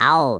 Worms speechbanks
ow3.wav